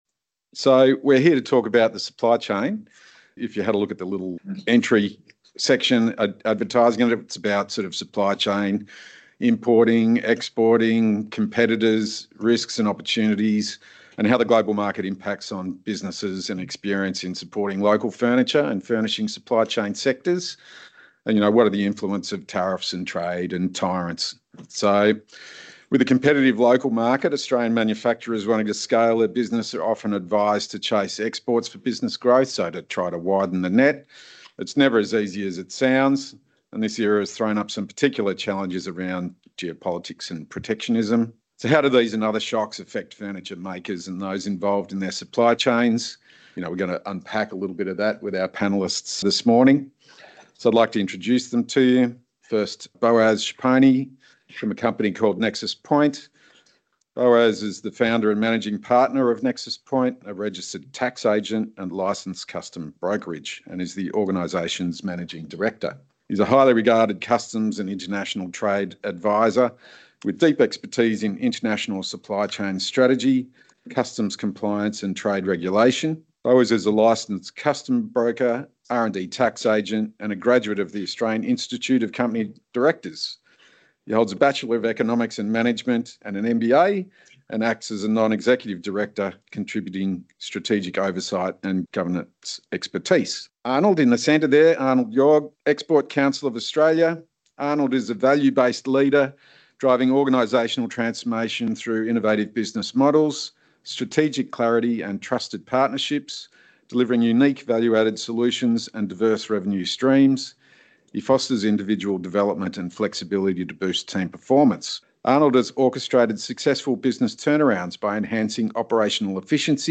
So how do these and other shocks affect furniture makers and those involved in their supply chains? This panel from day one of GPP 2026 gives an explanation of what’s going on in terms of tariffs, trade and tyrants shaping the global market, and how you can adapt to make the most of this moment.